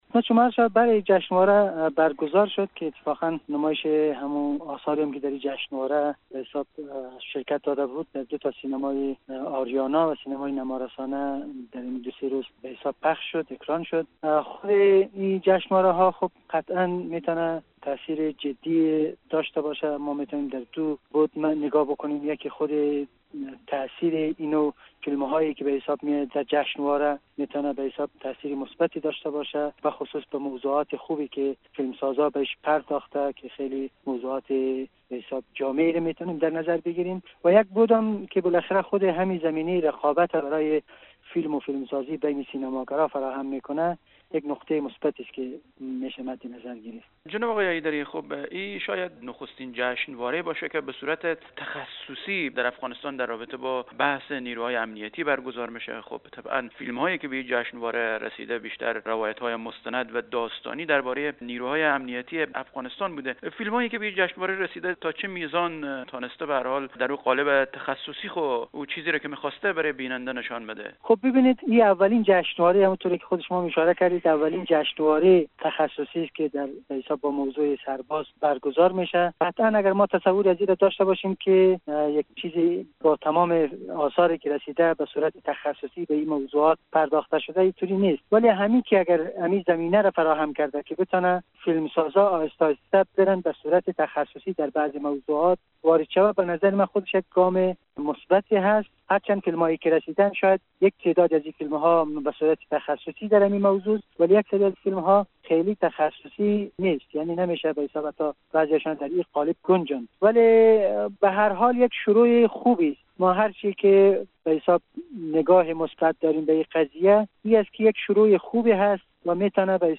گفت و گو با خبرنگار رادیو دری